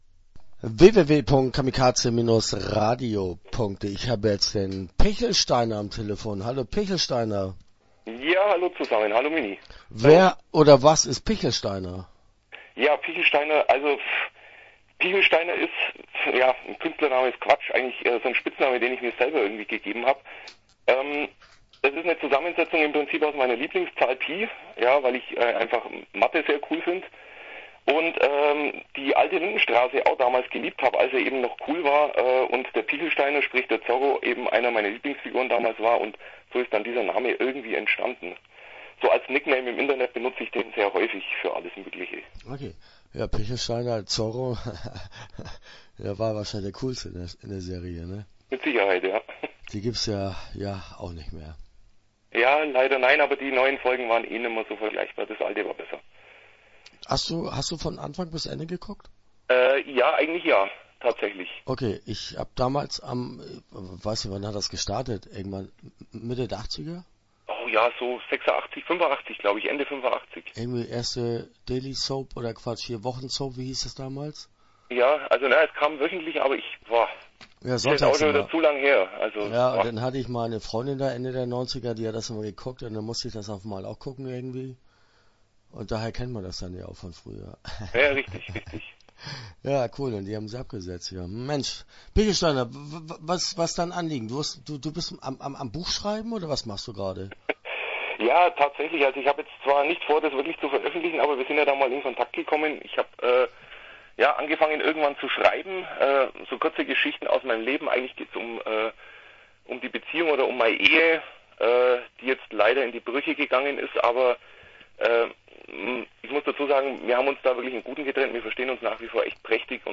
Interview Teil 1